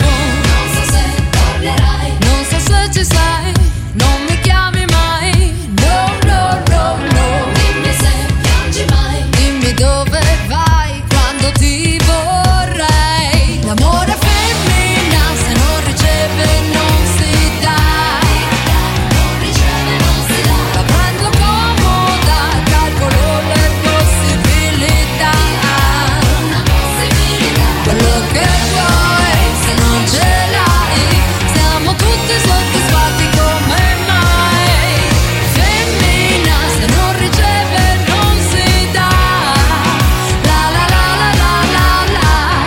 Genere: pop italiano